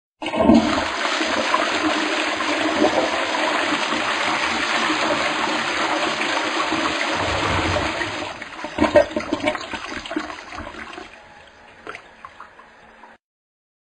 Klingelton Toilet Flush
Kategorien Soundeffekte